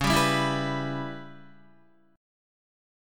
C#m7b5 chord